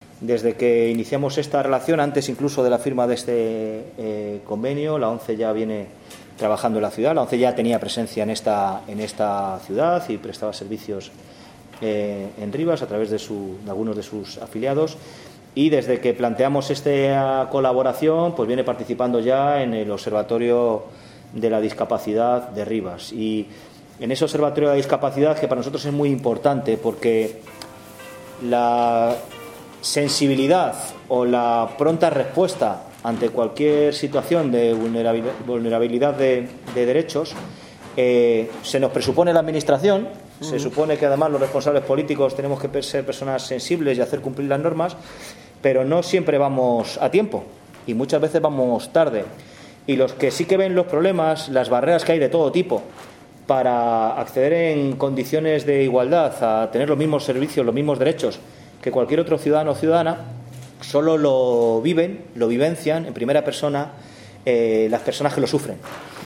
según explicó el alcalde de la localidad formato MP3 audio(1,12 MB).
PedrodelCuraalcaldedeRivas.mp3